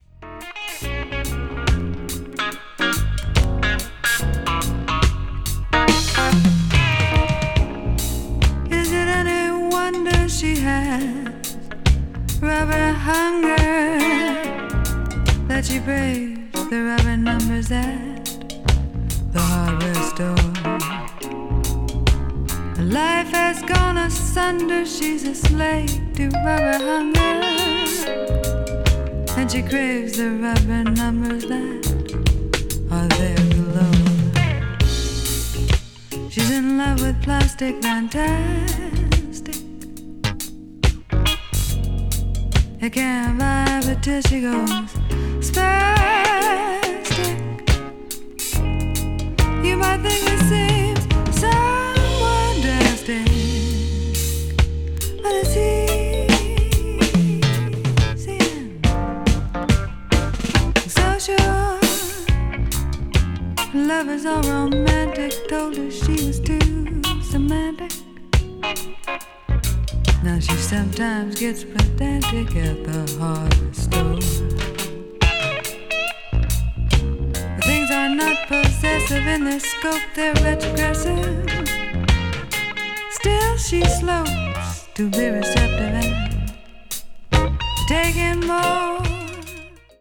avant-garde   avant-rock   blues rock   jazz rock